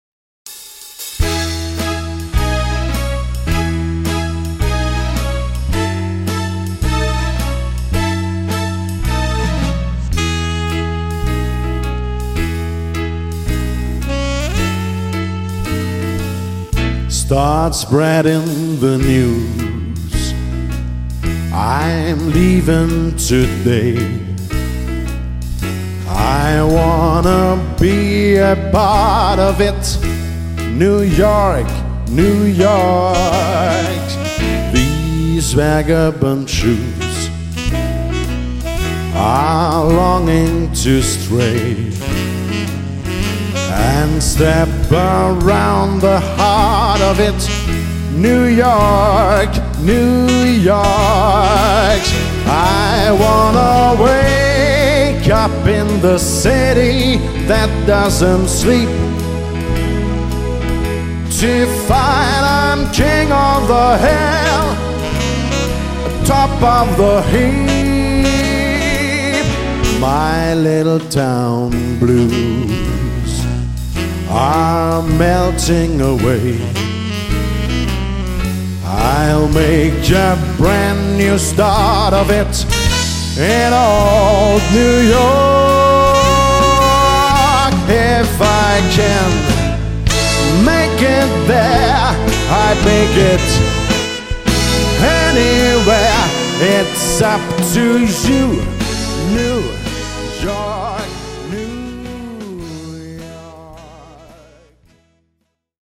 Tanzmusik  -  Soul  -  Funk  -  Pop  -  Rock